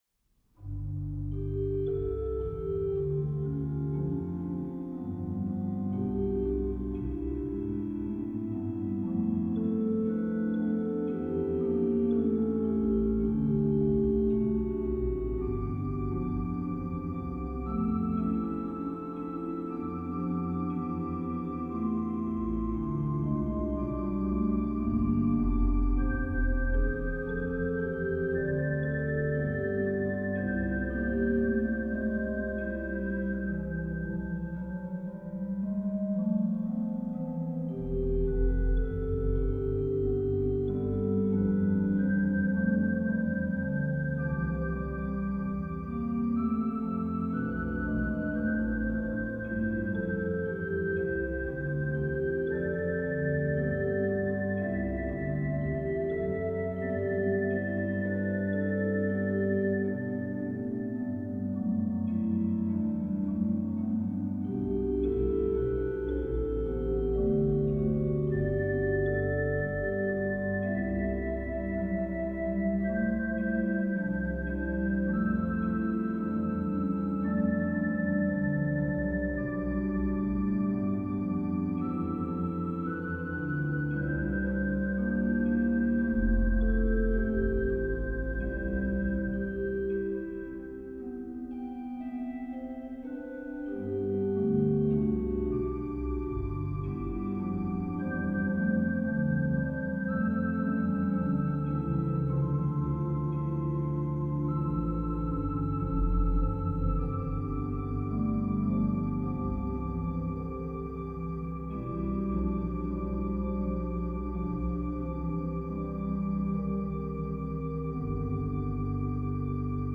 Beste Orgelliefhebber,
zijn koraalvoorspel.